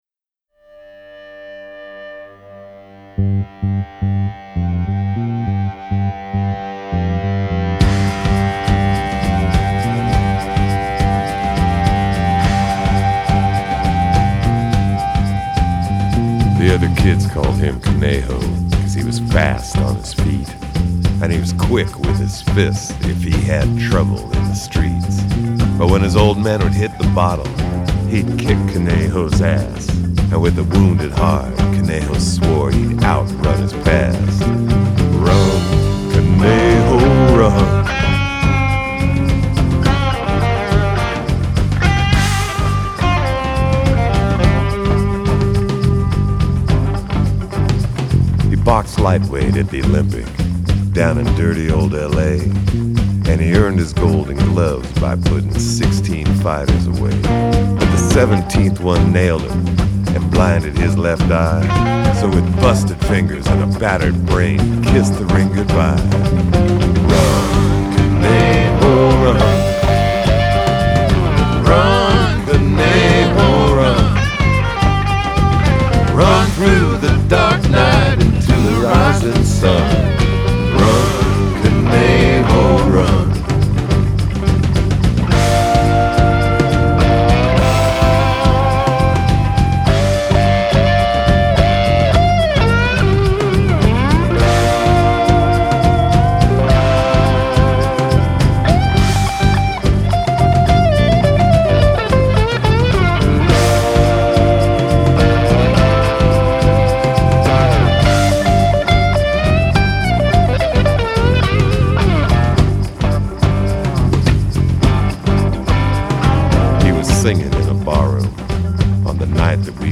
Жанр: Blues Rock, Country Rock